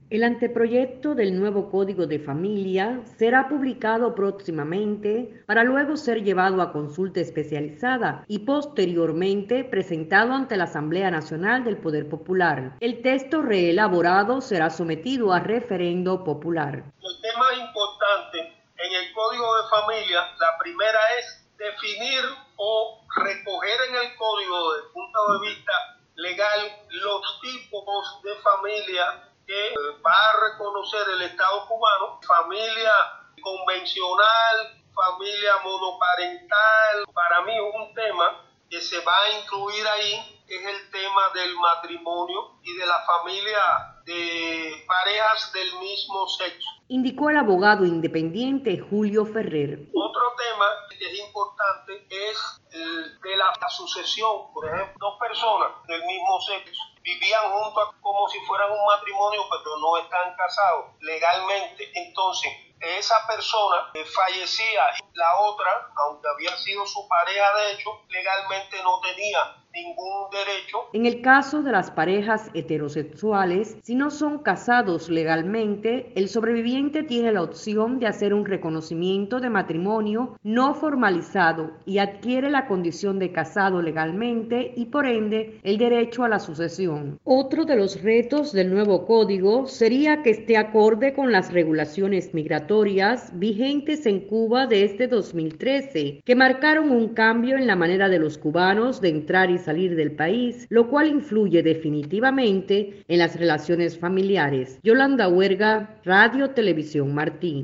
entrevisto al abogado independiente